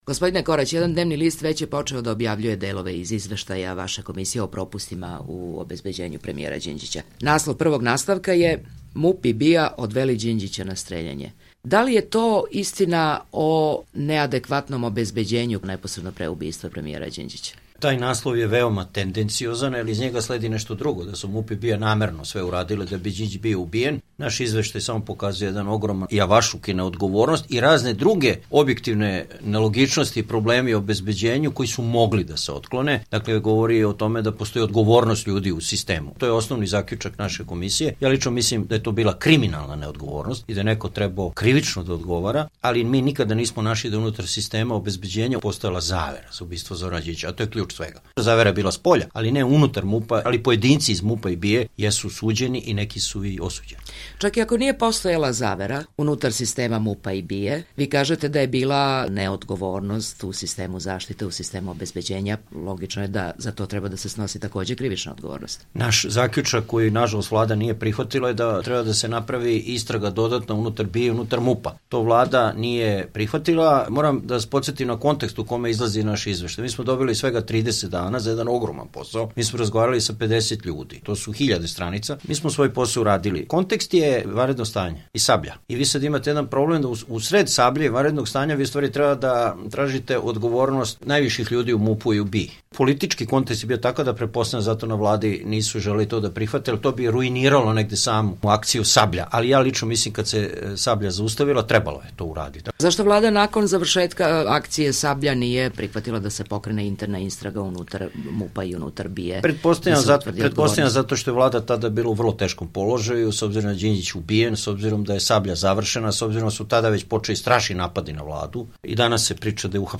Intervju nedelje: Žarko Korać